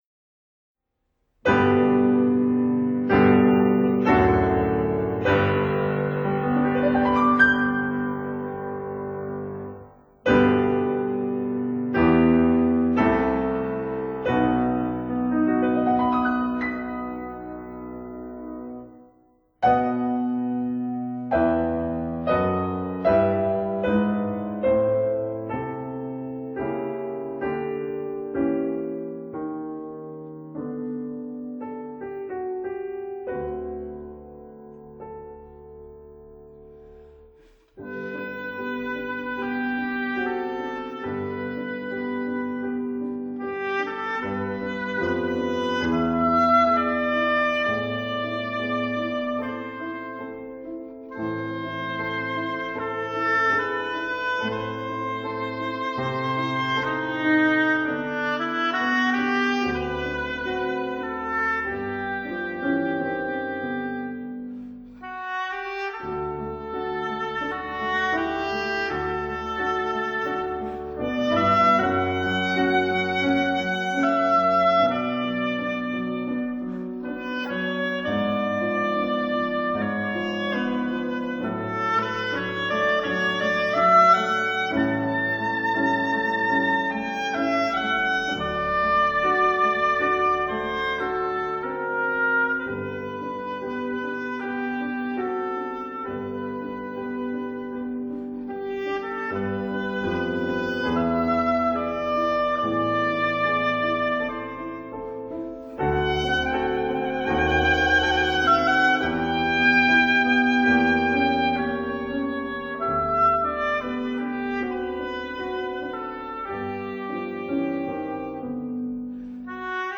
for oboe, cello and piano
piano
cello
oboe